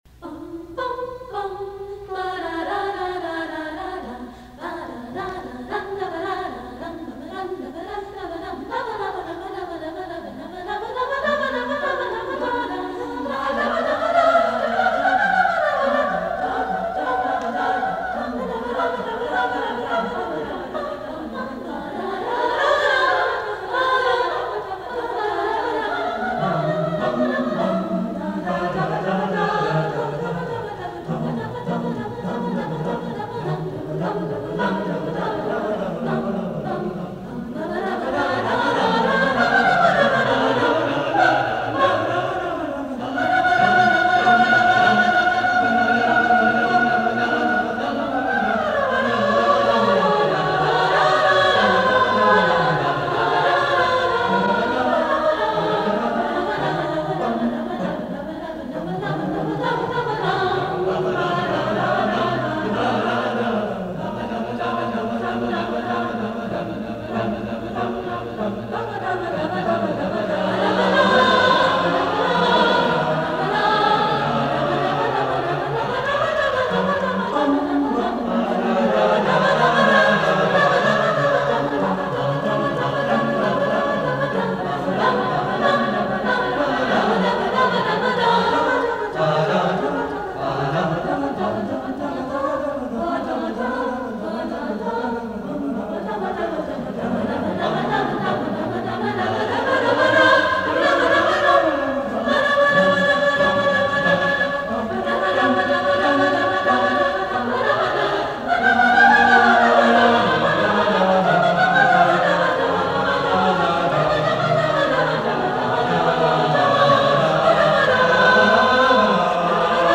1994-1995 Choir Recordings
The school year of 1994-1995 was very fun, choir-wise. we performed some great jazz songs (see Blue Skies and Come Home), a jazzed-up vocal version of Bach’s Organ Fugue, and some high-energy crowd pleasers (Bridge Over Troubled Water, Steal Away, and Betelehemu).